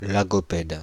Prononciation
Synonymes ptarmigan Prononciation Paris: IPA: [la.ɡɔ.pɛd] France (Île-de-France): IPA: /la.ɡɔ.pɛd/ Le mot recherché trouvé avec ces langues de source: français Traduction 1.